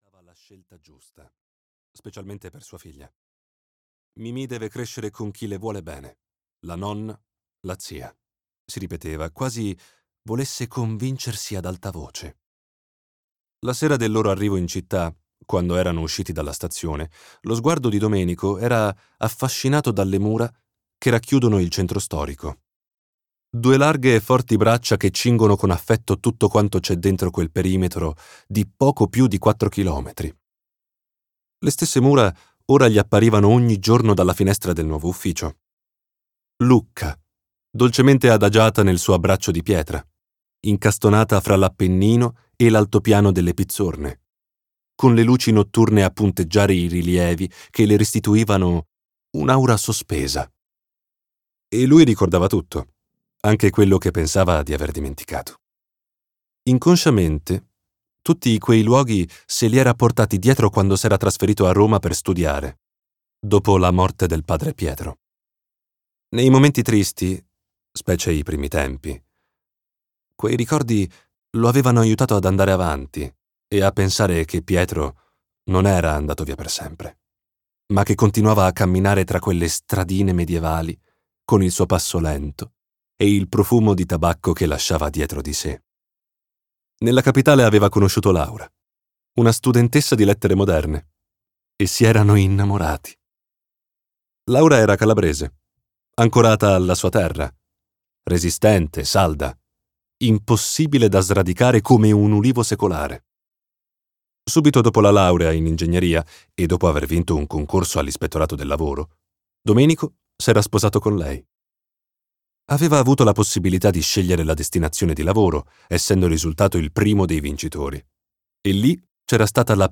Vietato pensare" di Pasquale Sgrò - Audiolibro digitale - AUDIOLIBRI LIQUIDI - Il Libraio